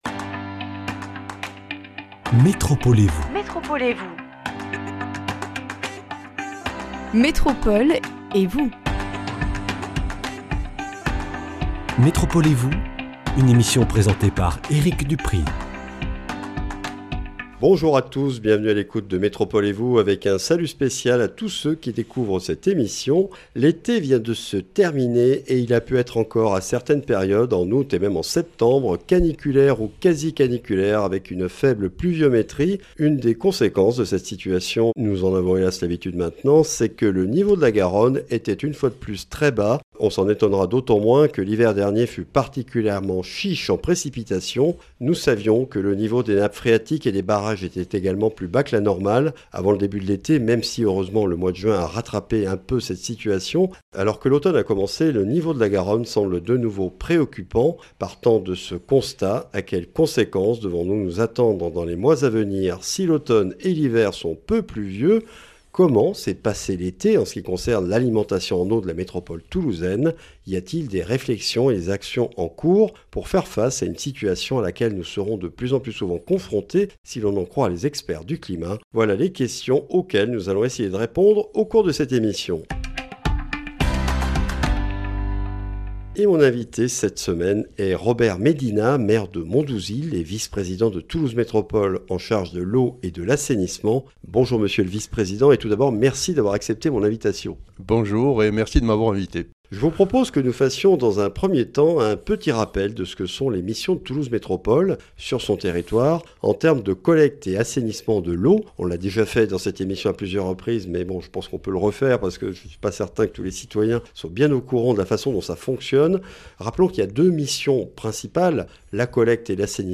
Après le retour de températures très élevées et d’une relative sécheresse depuis août, quelle est la situation aujourd’hui ? Un point avec Robert Médina, maire de Mondouzil et vice-président de Toulouse Métropole (Eau et Assainissement).